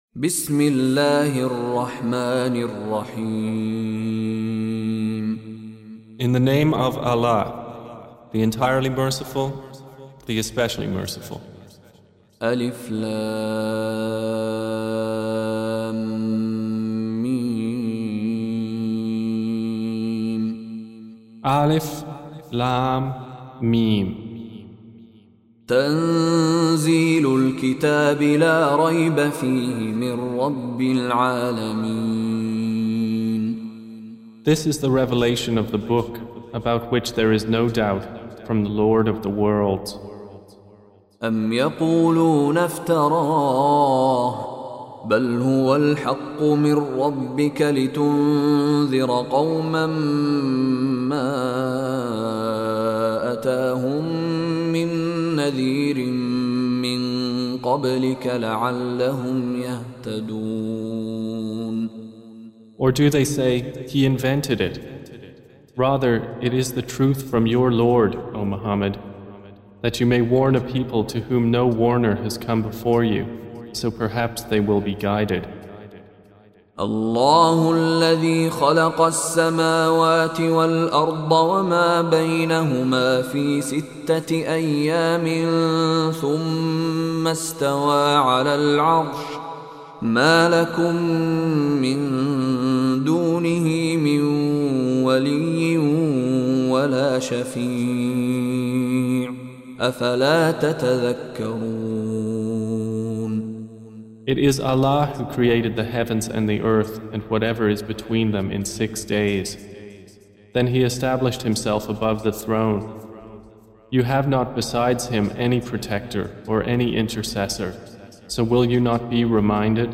Audio Quran Tarjuman Translation Recitation